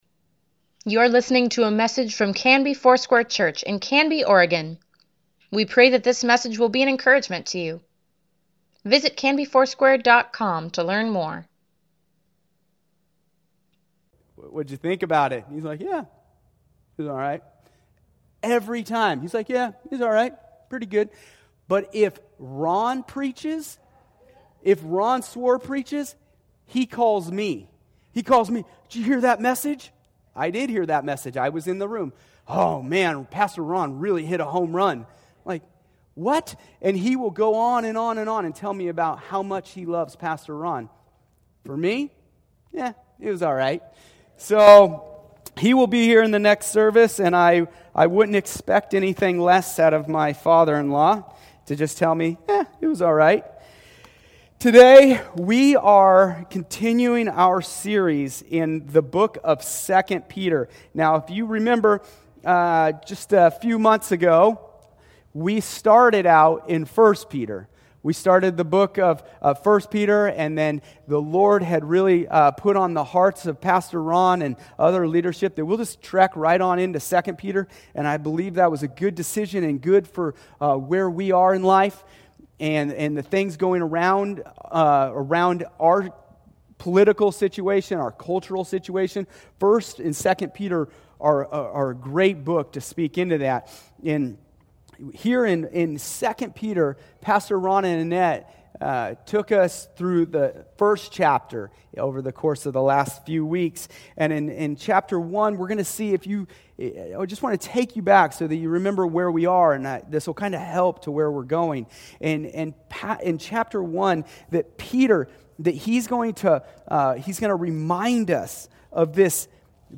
Weekly Email Water Baptism Prayer Events Sermons Give Care for Carus Steadfast 2; part 3 October 25, 2020